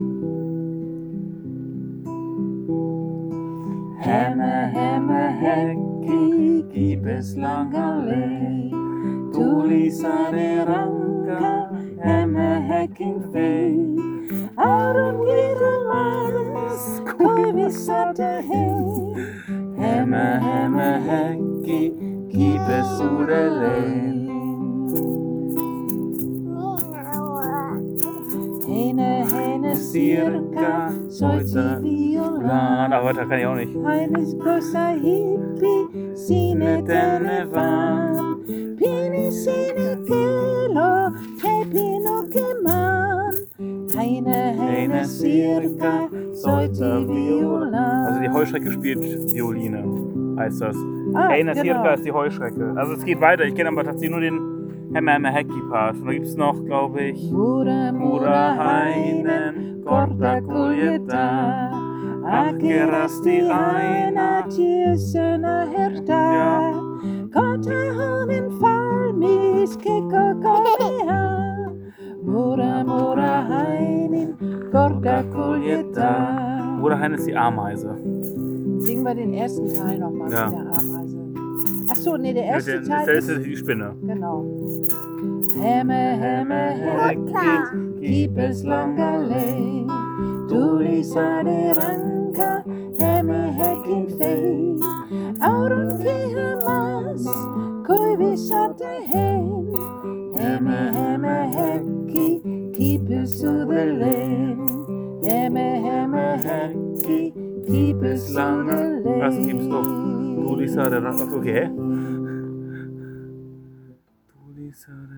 Auf dem zentralen Spielplatz im Viertel mit und für Kinder und Eltern und Großeltern zu singen war ein wunderbares Projekt.
Gemeinsames singen, nette Gesellschaft, fröhliche Kinder und trockenes Wetter.
Hämä-Hämähäkki Ein finnisches Kinderlied- auf dem Spielplatz neu gelernt in diesem Sommer.